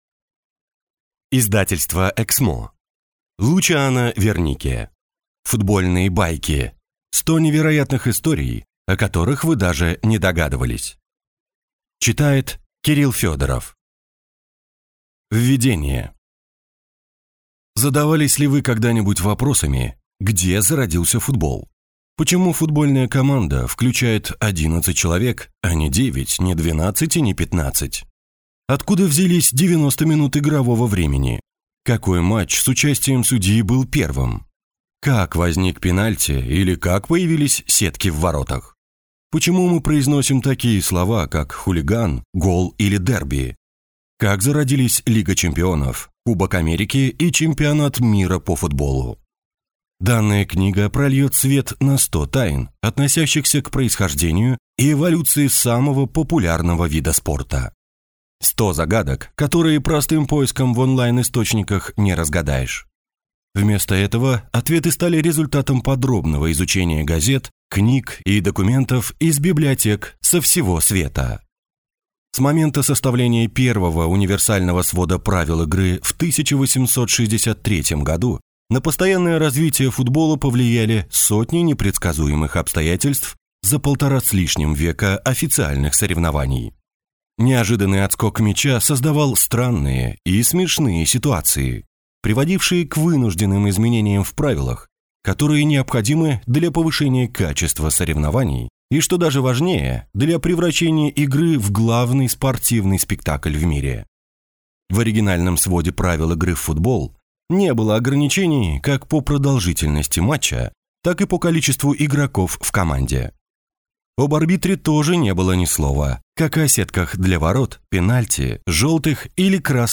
Аудиокнига Футбольные байки: 100 невероятных историй, о которых вы даже не догадывались | Библиотека аудиокниг